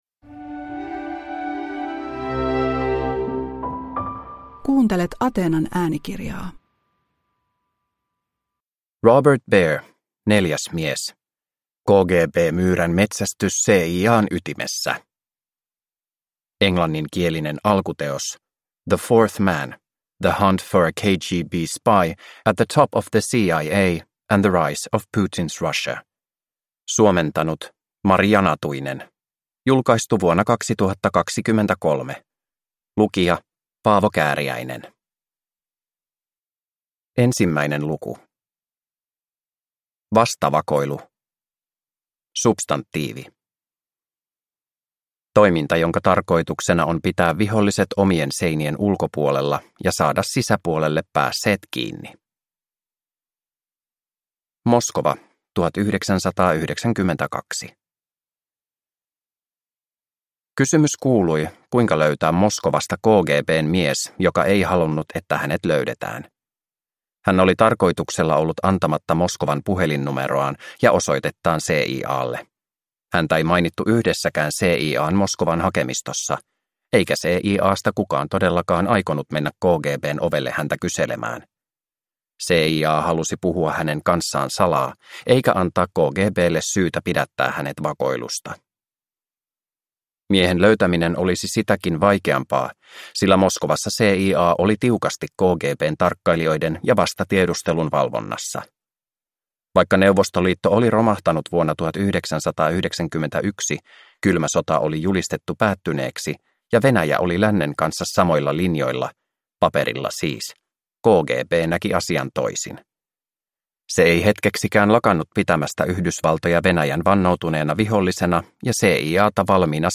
Neljäs mies – Ljudbok – Laddas ner